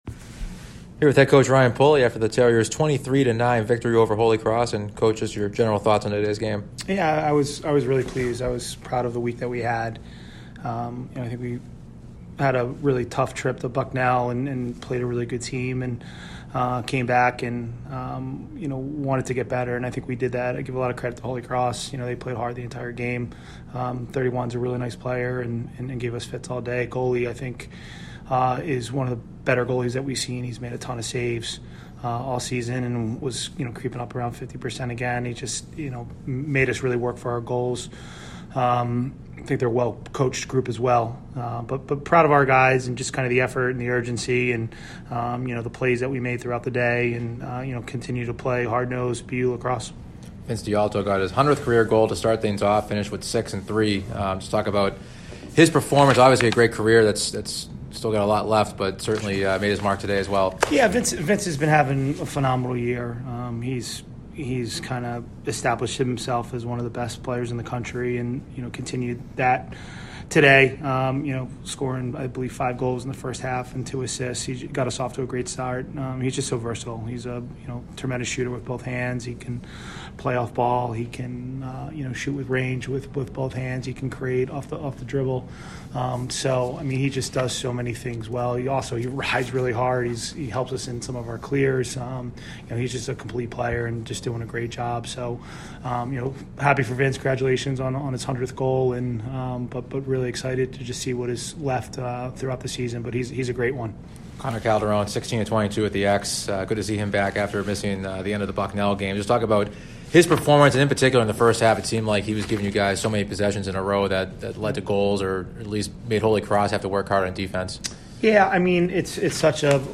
Boston University Athletics